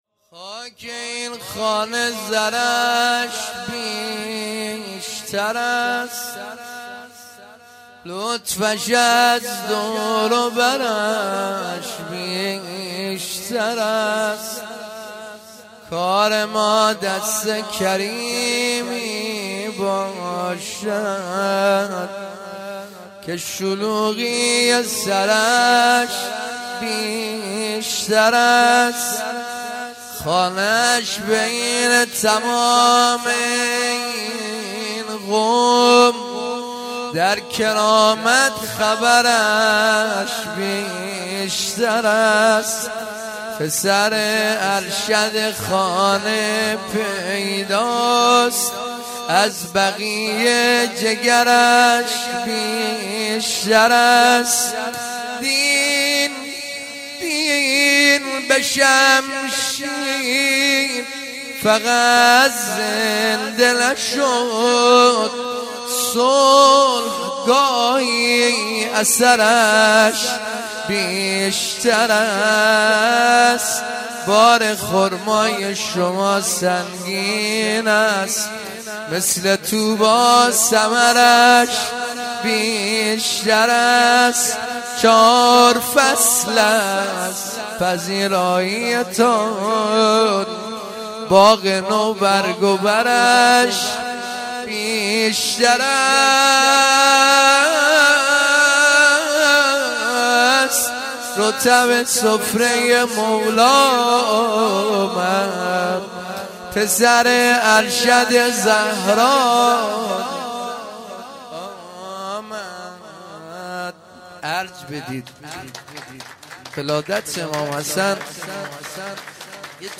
مدح
مناجات هفتگی)جشن ولادت کریم اهل بیت ع ـ جمعه۱۱خرداد ـ رمضان۱۴۳۹